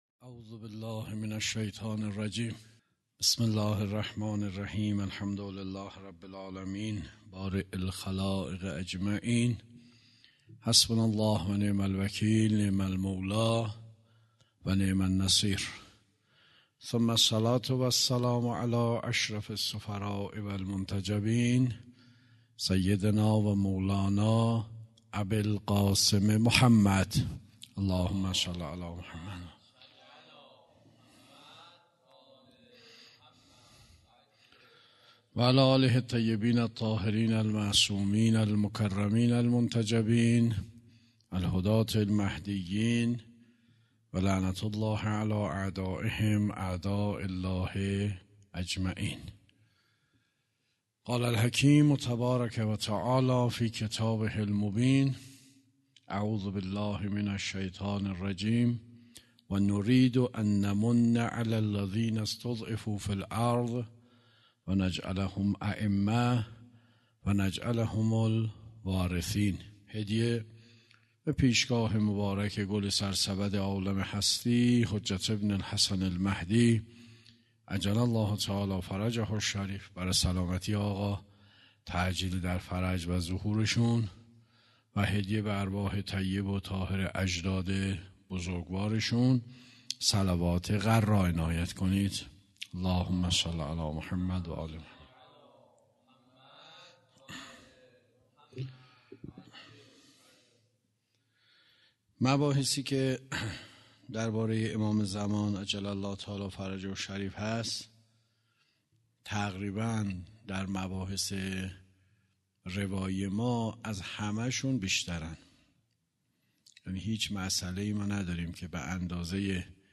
سخنرانی
جلسه هفتگی | ۱۶ آذر ۱۳۹۵